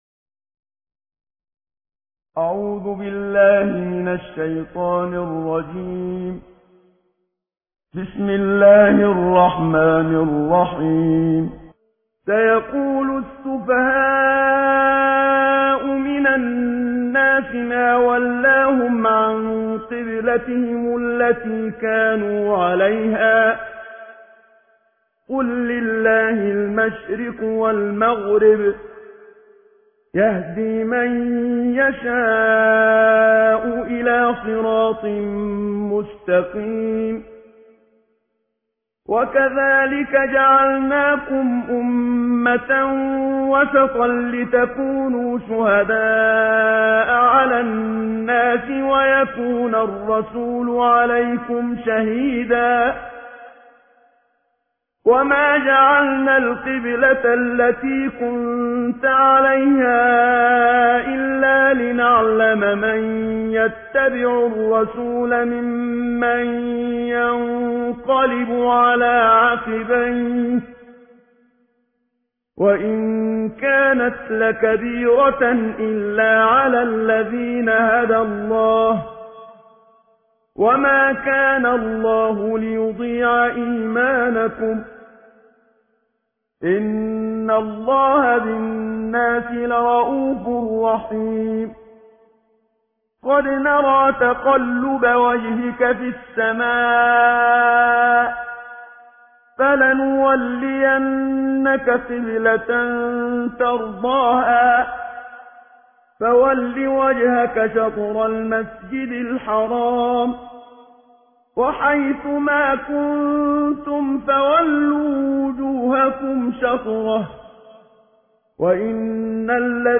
تلاوت جزء دوم قرآن کریم با نوای استاد منشاوی/متن و ترجمه